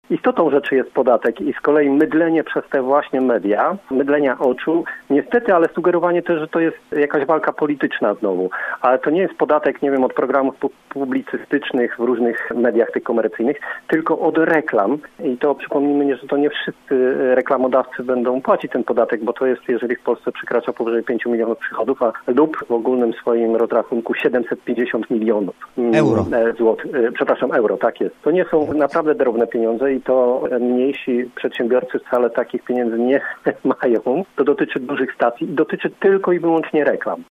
Przewodniczący zielonogórskiej Rady Miasta komentuje wczorajszy protest części mediów przeciwko proponowanemu przez rząd podatkowi od przychodów z reklam. Piotr Barczak był gościem Rozmowy po 9.